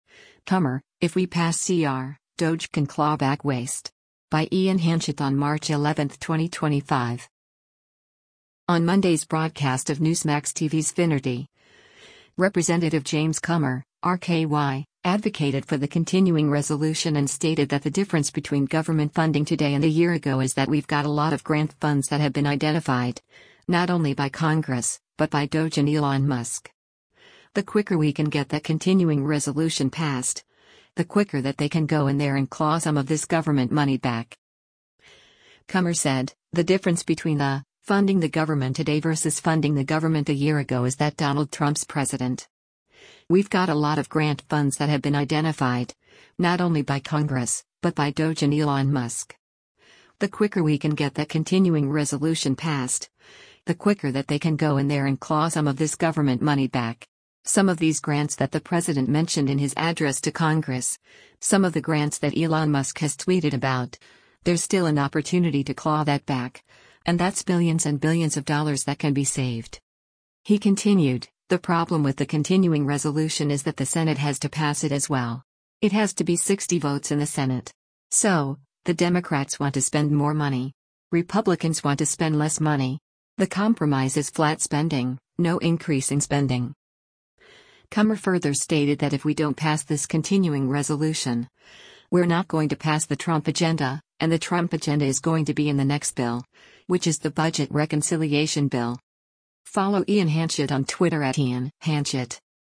On Monday’s broadcast of Newsmax TV’s “Finnerty,” Rep. James Comer (R-KY) advocated for the continuing resolution and stated that the difference between government funding today and a year ago is that “we’ve got a lot of grant funds that have been identified, not only by Congress, but by DOGE and Elon Musk. The quicker we can get that continuing resolution passed, the quicker that they can go in there and claw some of this government money back.”